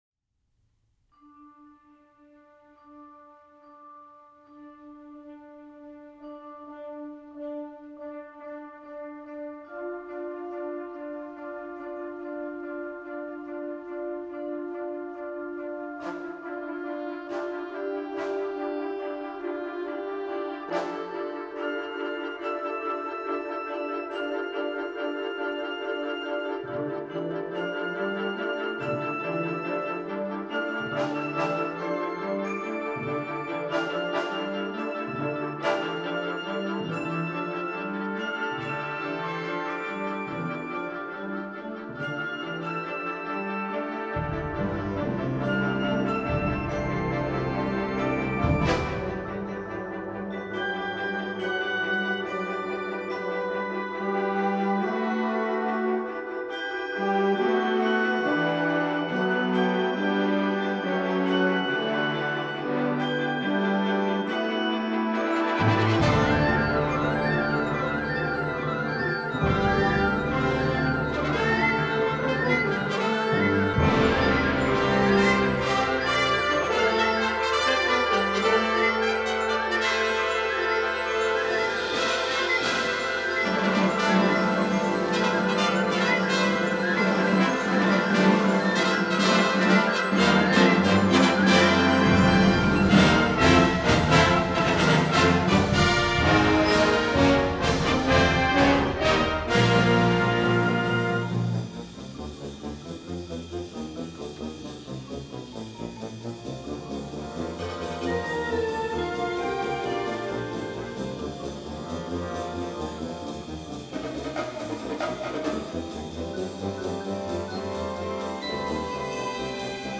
Genre: Band
Timpani (4 drums)
Percussion 5 (marimba, tenor drum, chimes)